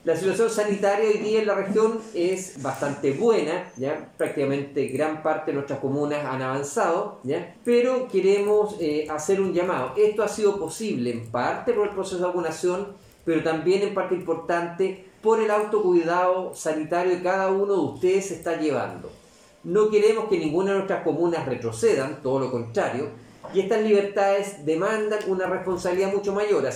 Por su parte, el Seremi de Salud de la región de Los Lagos, Alejandro Caroca, se refirió a la reducción del toque de queda en la zona, enfatizando bastante en la responsabilidad que tiene la población para mantener las libertades otorgadas.